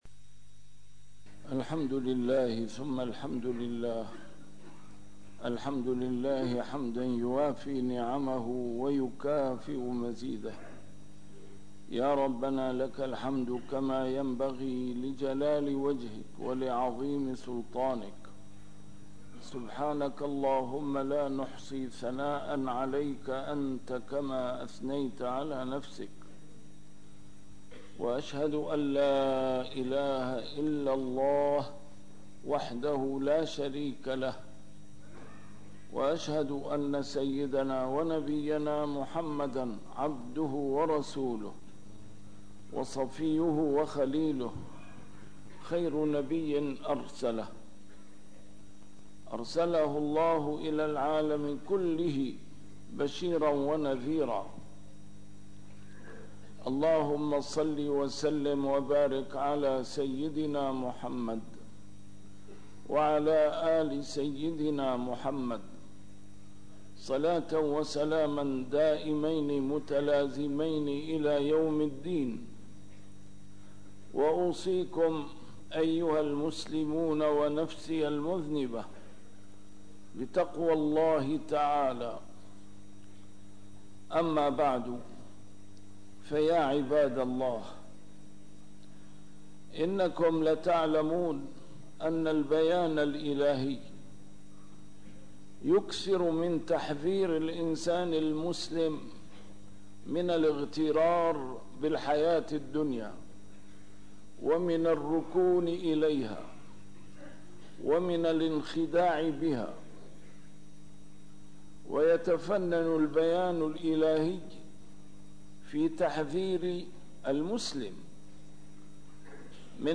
A MARTYR SCHOLAR: IMAM MUHAMMAD SAEED RAMADAN AL-BOUTI - الخطب - منهج المسلم في التعامل مع الدنيا